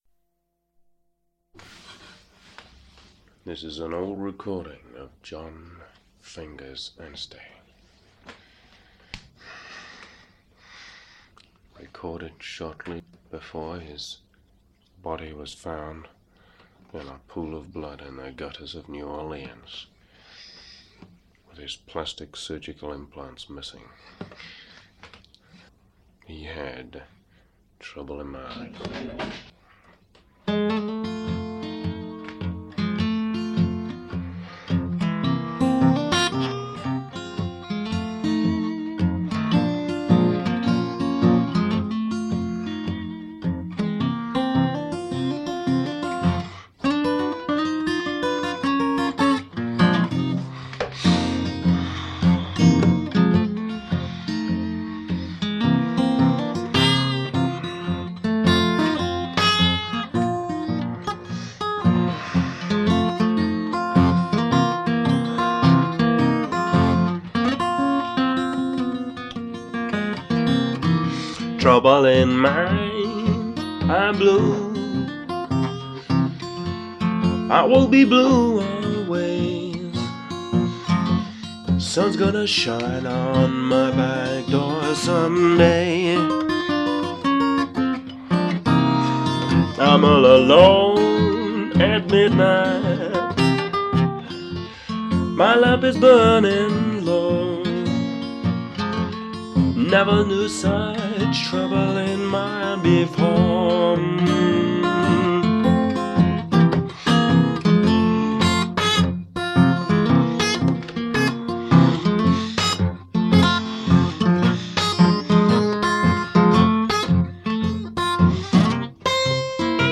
So I casually recorded as many as I could remember in a couple of fairly… well not so much fairly as downright crudely… mono recording sessions.
This particular recording – with its quirky not to say eccentric intro and epilogue – still amuses (and embarrasses) me to this day; singing a blues number with a bad cold sounded like a bad idea at the time, but thinking about some of the words it could have been appropriate…